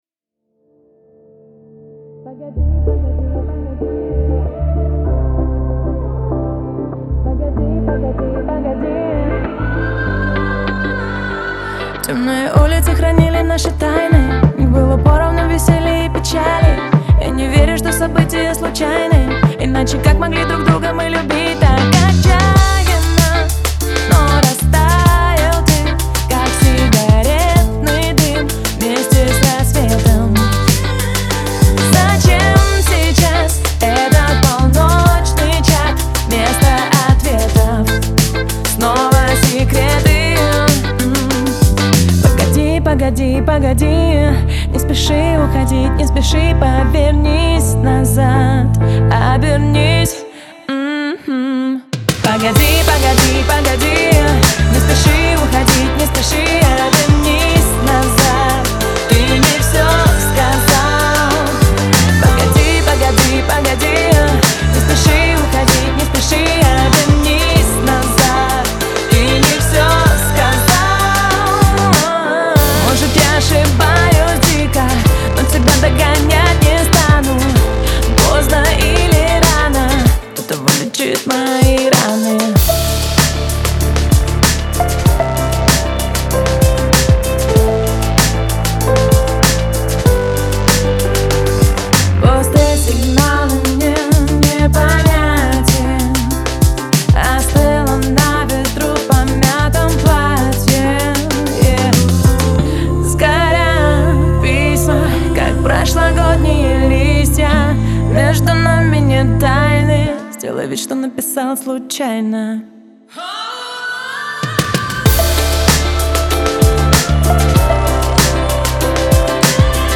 это эмоциональный трек в жанре поп с элементами электроники.